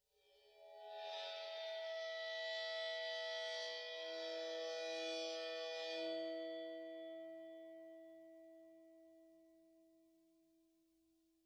susCymb1-bow-3.wav